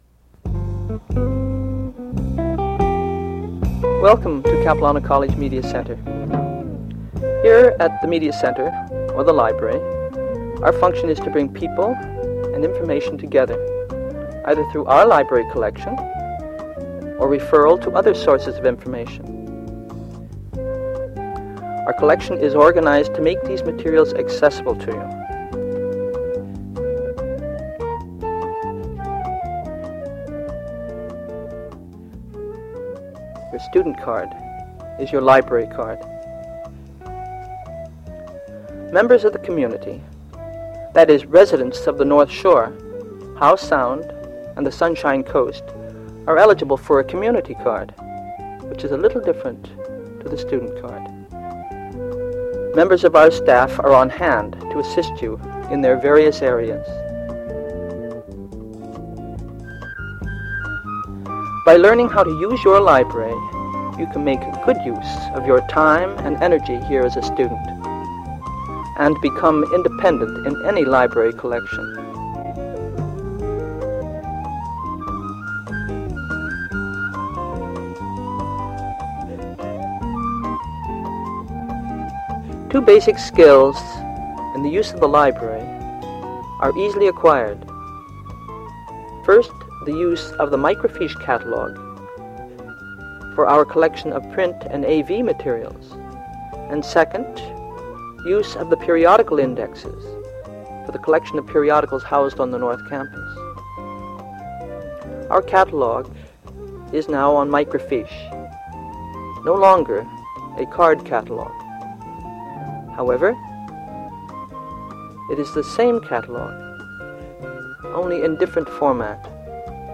Audio non-musical
Voiceover narrative with background music, describing the services and benefits of the Media Centre.
audio cassette